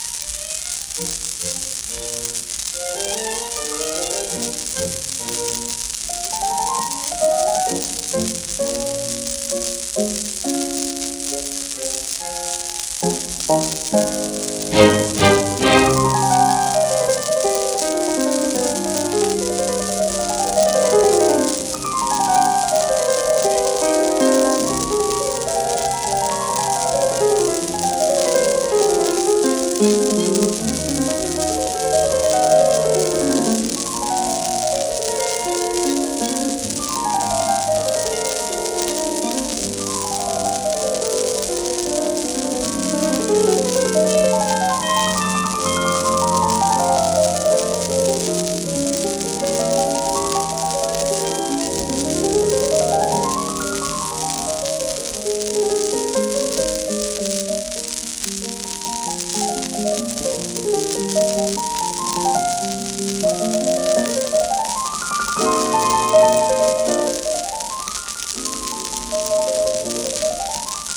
ピアノ協奏曲第22番変ホ長調 その２